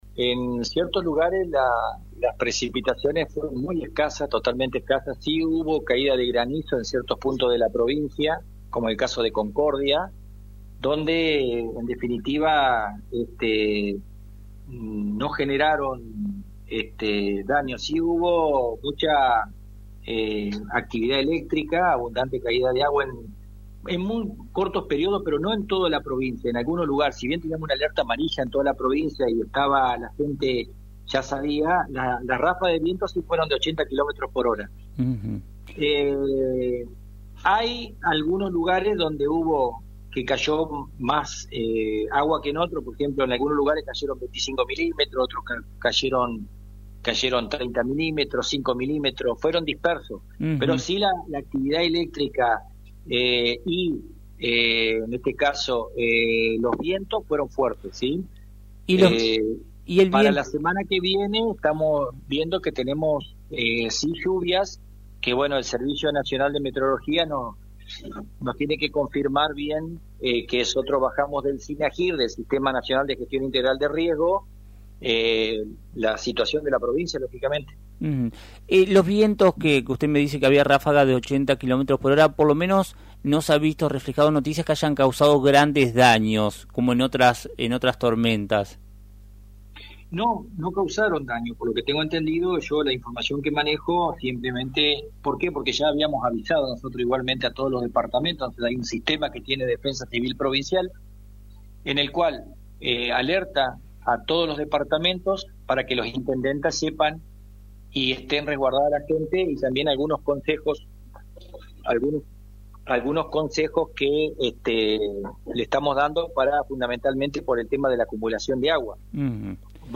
El Director de Defensa Civil, Roberto Daniel Borré, habló en Radio Victoria sobre las condiciones climáticas recientes, la gestión ante emergencias y los esfuerzos conjuntos para combatir los incendios en el Delta del Paraná.